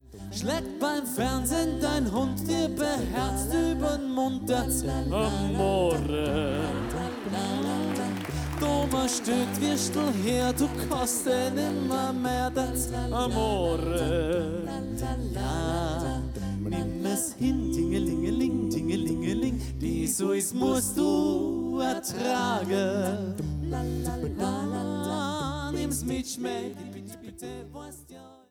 a cappella-Konzertpackage